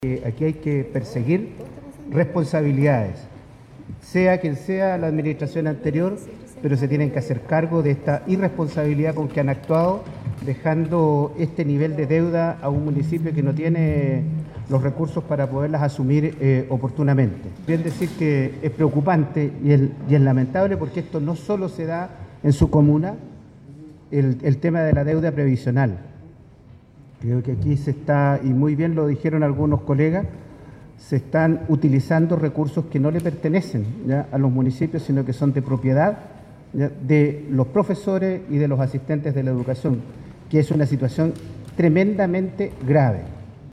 Dispares opiniones generó entre autoridades y parlamentarios la presentación que la delegación de Ancud realizó ante la comisión de Educación de la Cámara de Diputados, en la jornada del lunes, en Santiago.
En esta línea, el diputado Sergio Bobadilla, de la UDI, expresó que esta situación deficitaria, ocurre en otras comunas, donde también se hace uso indiscriminado de los recursos que pertenecen a los profesores.